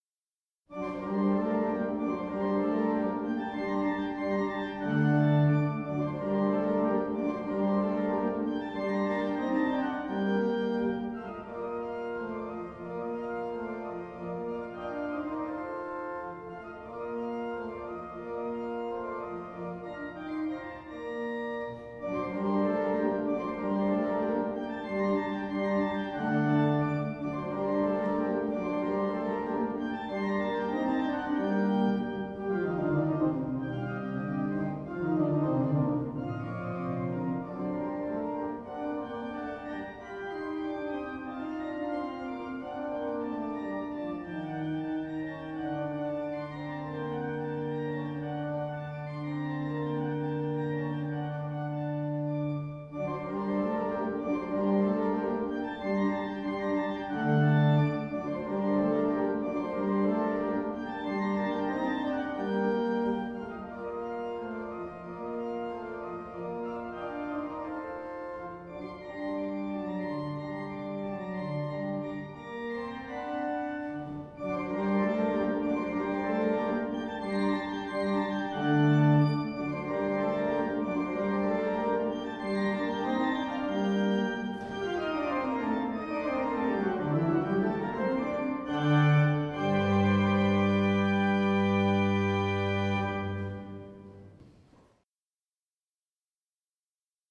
English choral music as it might have been sung
in the Priory Church through the centuries